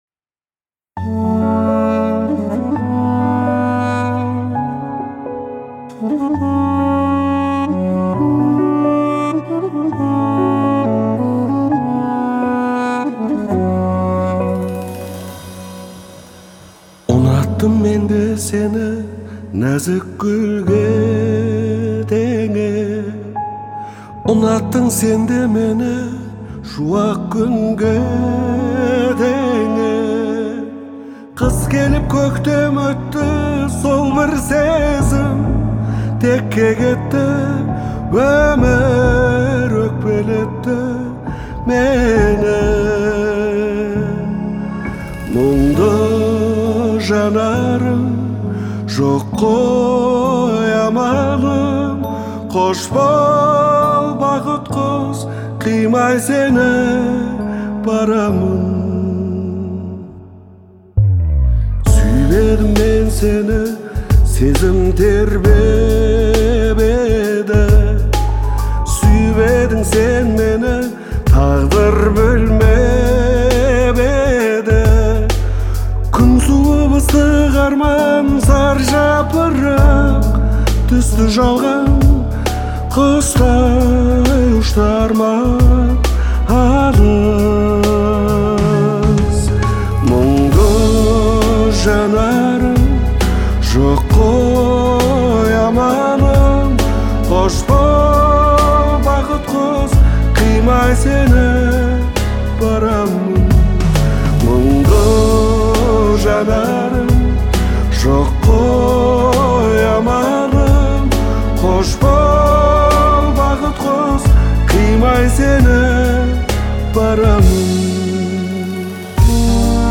сочетая традиционные мелодии с современными аранжировками.